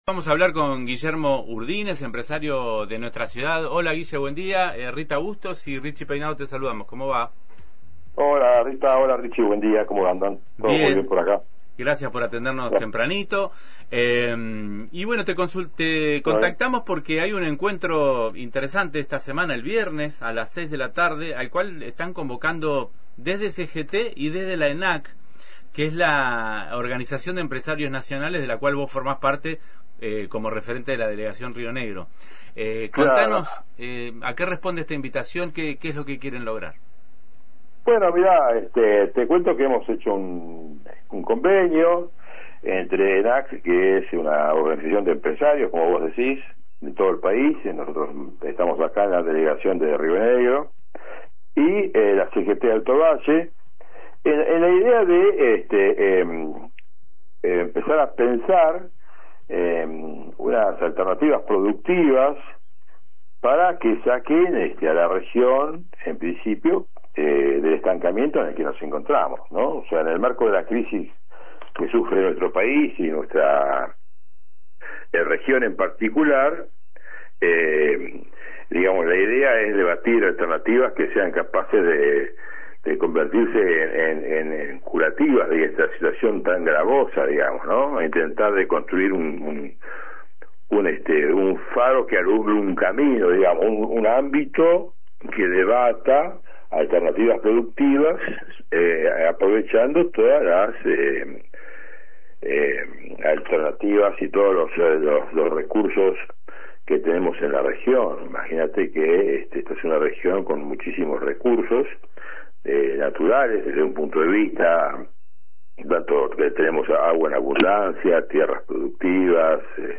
En diálogo con Antena Libre